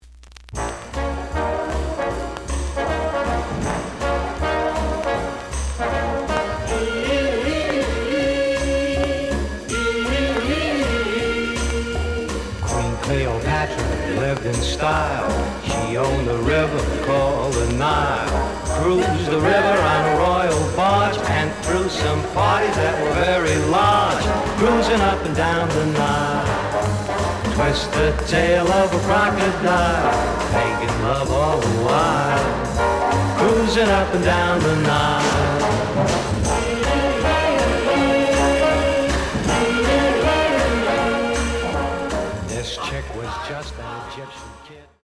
The audio sample is recorded from the actual item.
●Format: 7 inch
●Genre: Jazz Other